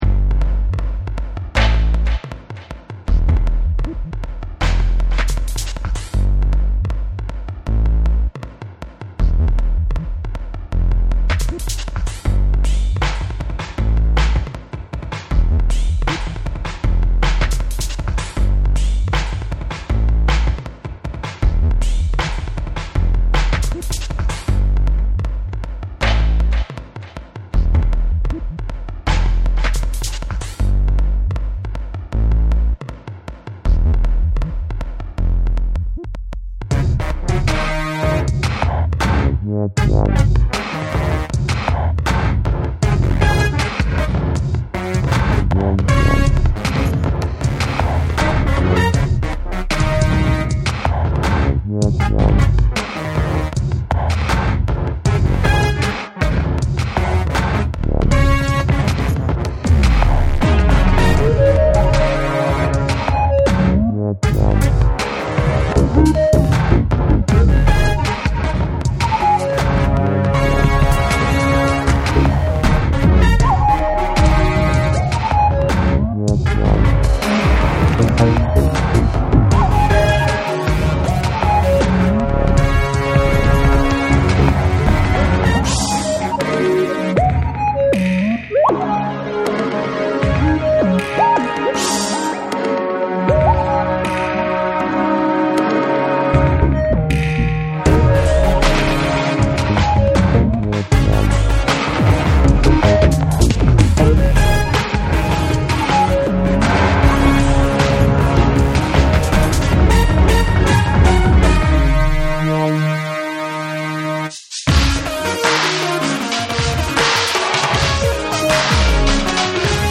That was a pretty long epic type thing, with micro pitch
control stuff over that high pitched whistle better than I